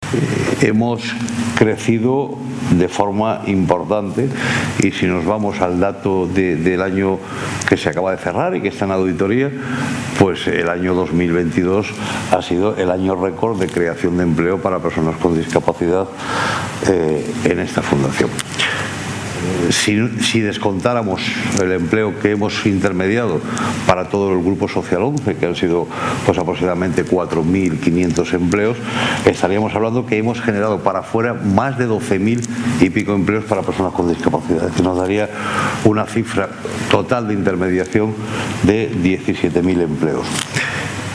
en el evento ‘El empleo público: una oportunidad para las personas con discapacidad’.Abre ventana nueva